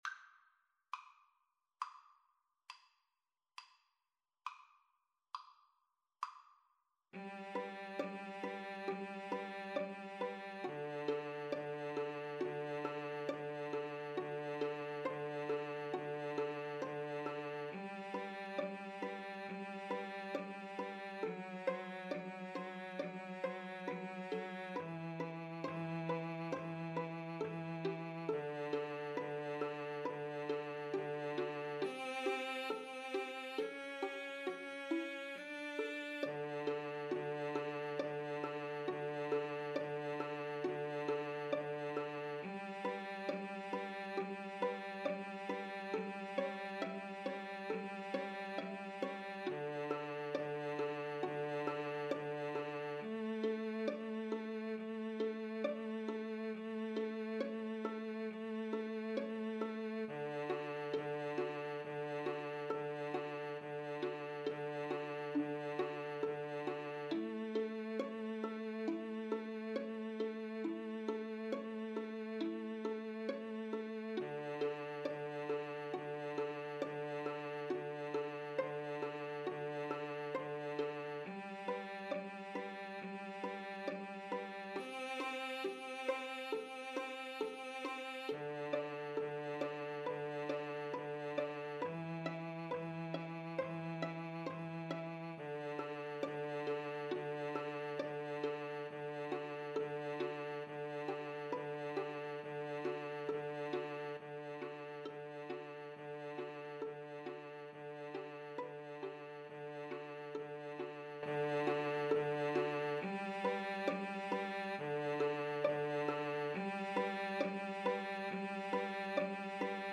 G major (Sounding Pitch) (View more G major Music for 2-Violins-Cello )
= 34 Grave
Classical (View more Classical 2-Violins-Cello Music)